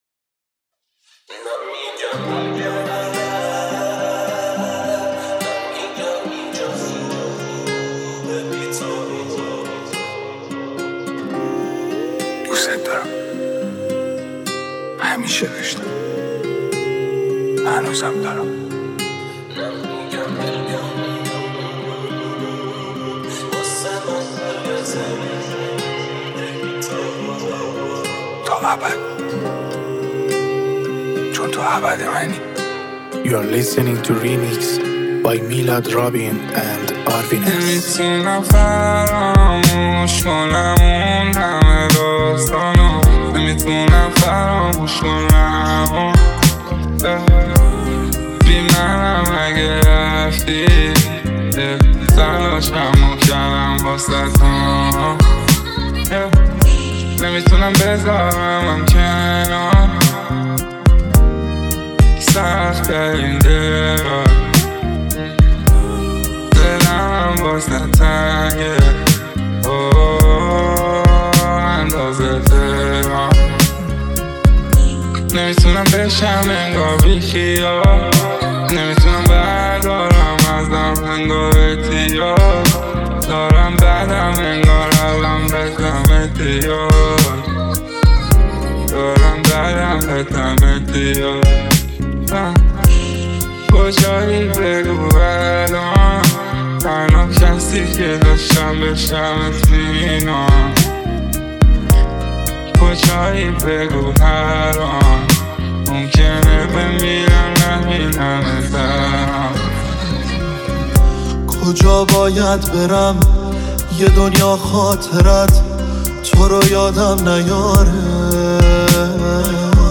ریمیکس رپی
ریمیکس جدید رپ